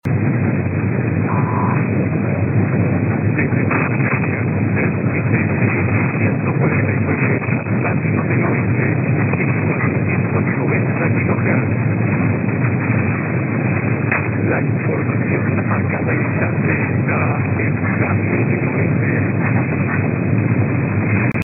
Radio del Oeste fue sintonizada en Escocia por un radioaficionado de 75 años
También identificó la presentación de las noticias de la emisora y una voz femenina en el cierre del registro.
Radio-del-Oeste-1490-ESCOCIA.mp3